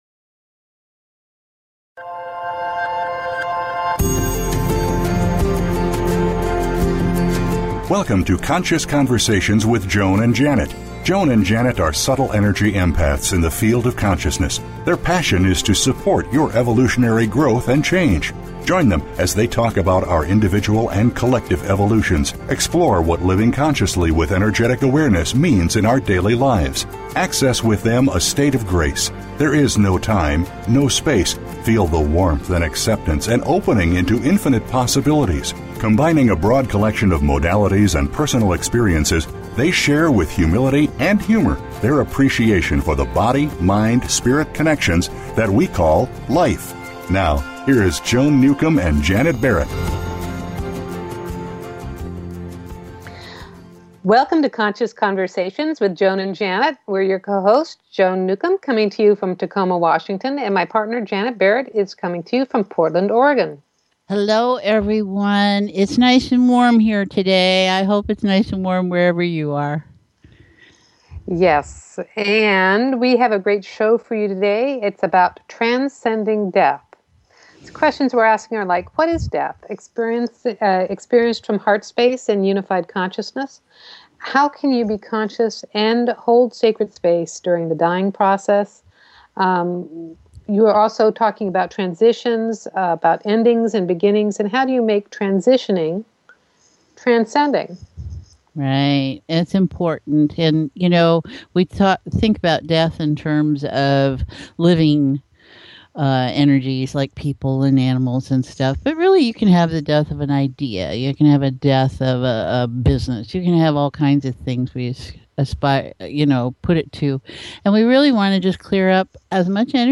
TODAY's SHOW IS LIVE & WE ARE TAKING CALLERS!